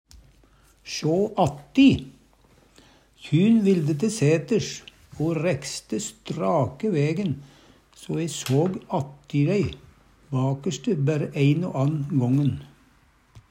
sjå atti - Numedalsmål (en-US)